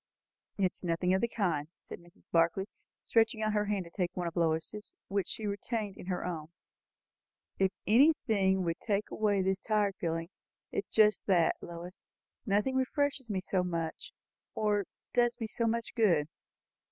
woman_4.wav